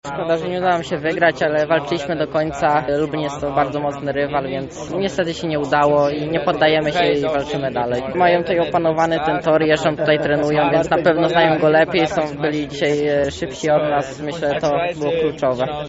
Podsumował piątkowy występ zawodnik drużyny gości